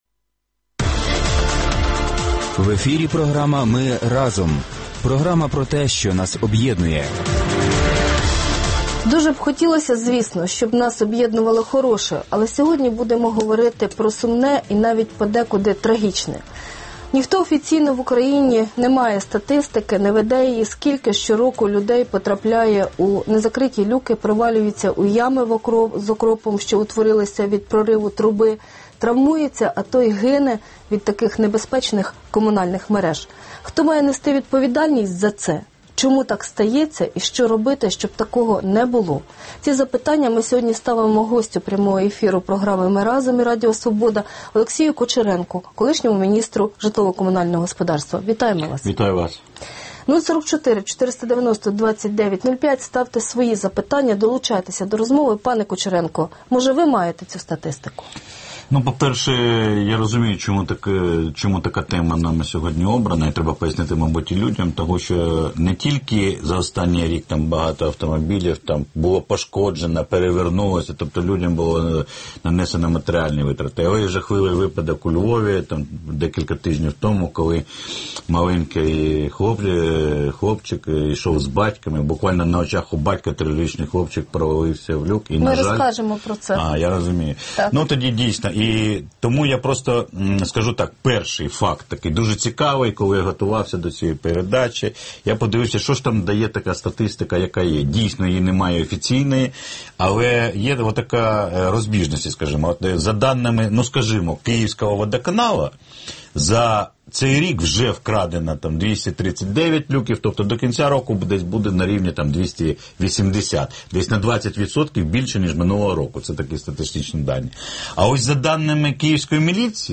Гість ефіру: Олексій Кучеренко, колишній міністр житлово-комунального господарства